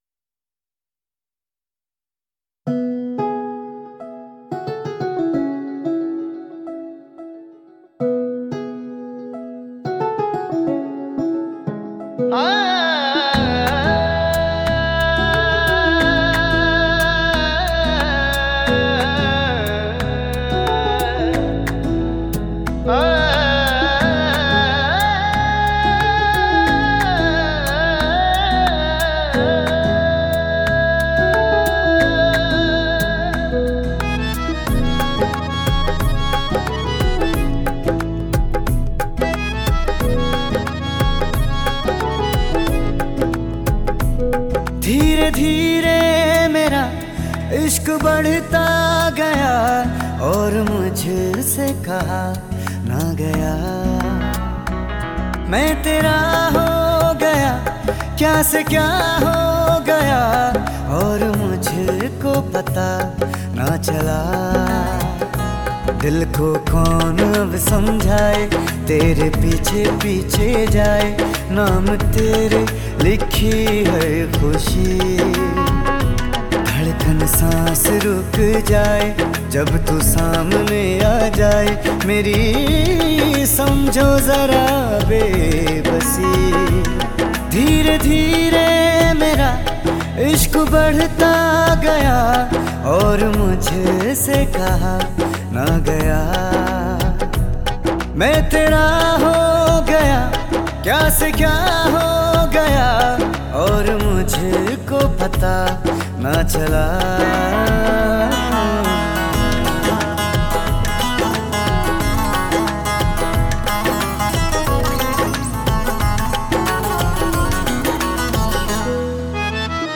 2. INDIPOP MP3 Songs